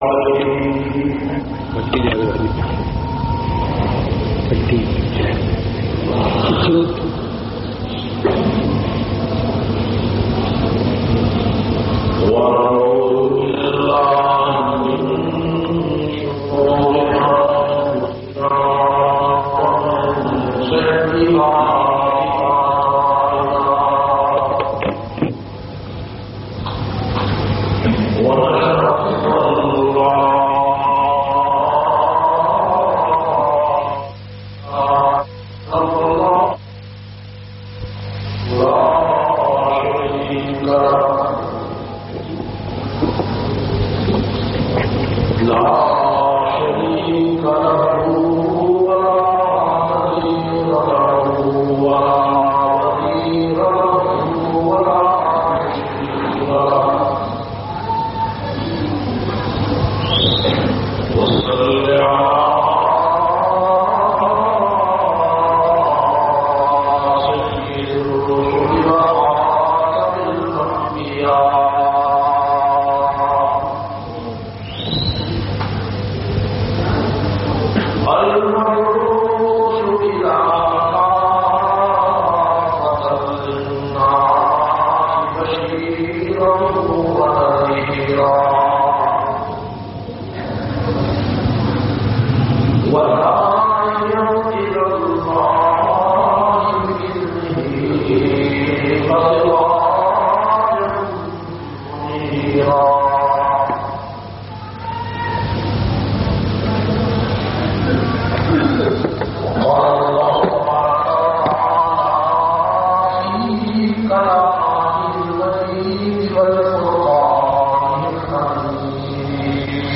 470- Hazrat Suleman A.s Jumma khutba Jamia Masjid Muhammadia Samandri Faisalabad.mp3